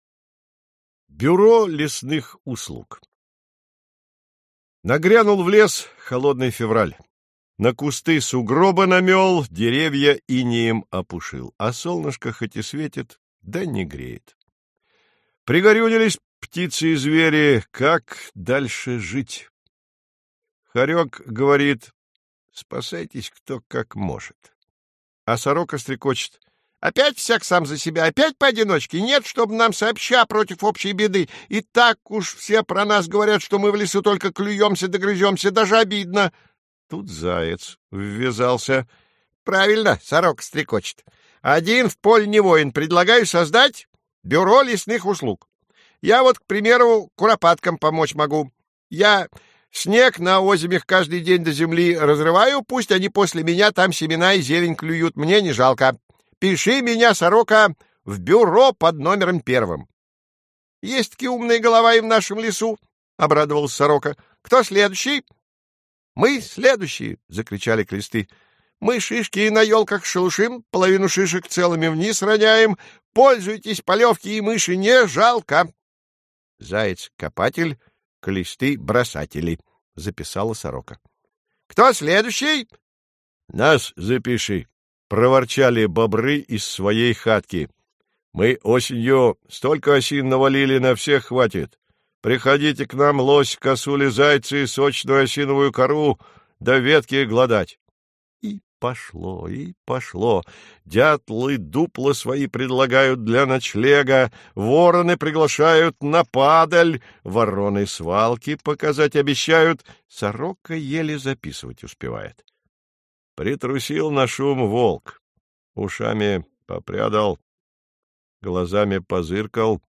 Аудиосказка «Бюро лесных услуг»